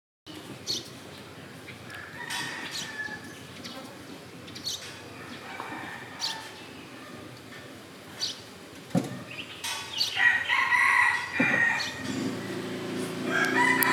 เสียงบรรยากาศ
ab-วัดภูเก็ต.mp3